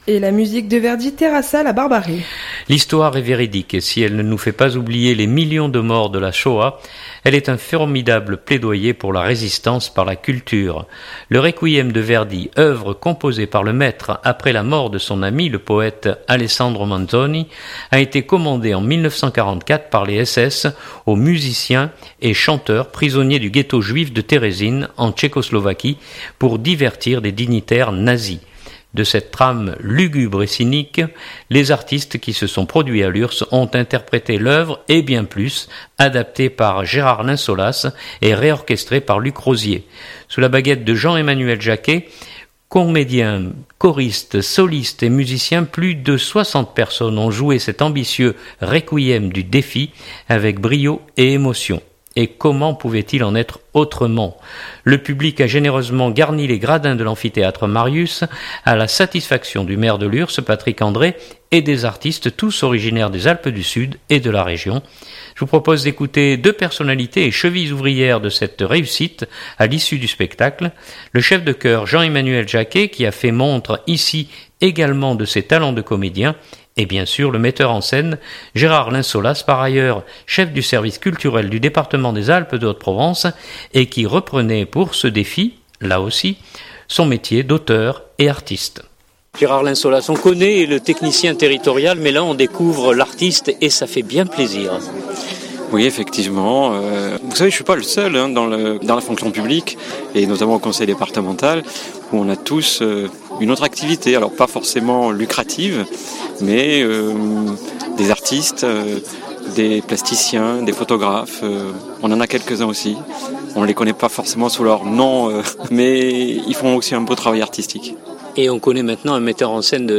Je vous propose d’écouter deux personnalités et chevilles ouvrières de cette réussite à l’issue du spectacle.